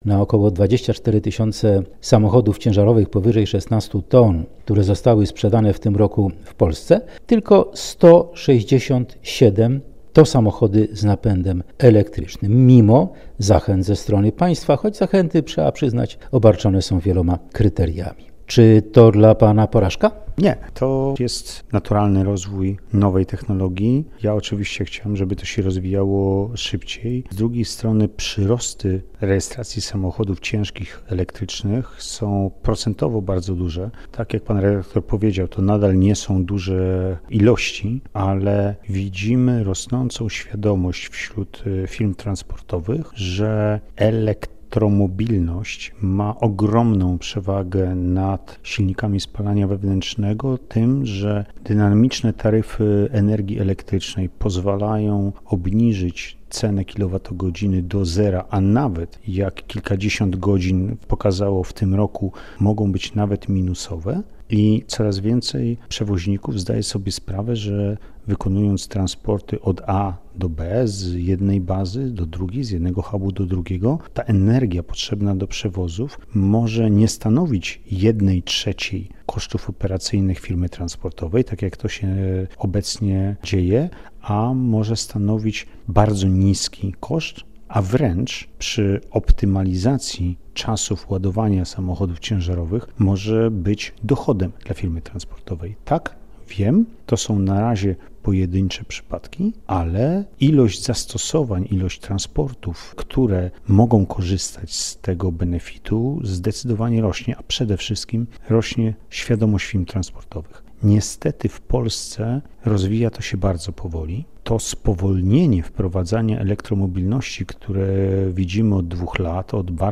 Ekspert o ciężarówkach w Polsce